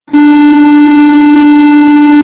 D-tune
snaar4D.amr